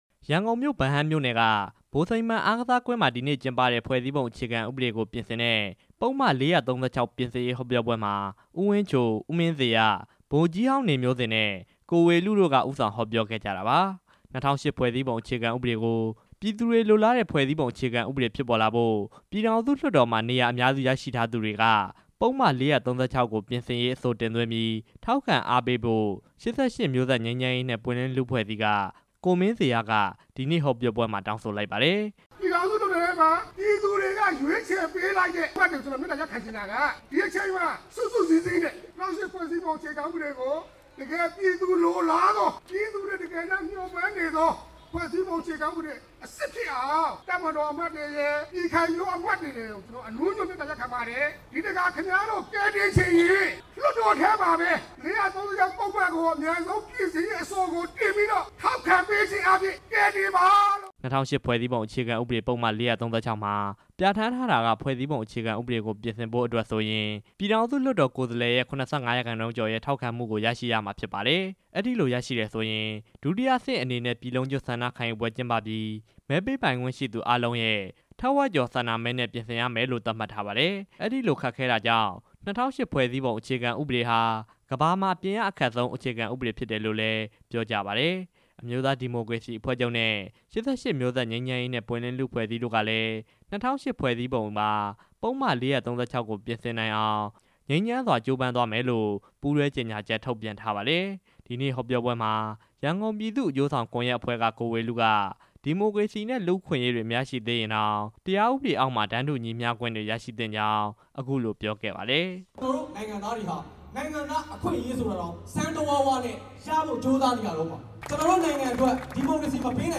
ဖွဲ့စည်းပုံဥပဒေ ပြင်ဆင်ရေး လူထုဟောပြောပွဲ ဗဟန်းမှာ ကျင်းပ
ရန်ကုန်မြို့ ဗဟန်းမြို့နယ် ဗိုလ်စိန်မှန် အားကစားကွင်းမှာ ဒီနေ့ကျင်းပတဲ့ ဖွဲ့စည်းပုံ အခြေခံဥပဒေ ပြင်ဆင်ရေး ပုဒ်မ ၄၃၆ ကို ပြင်ဆင်ရေးဟောပြောပွဲမှာ ကိုမင်းဇေယျာက အခုလို တိုက်တွန်းခဲ့တာပါ။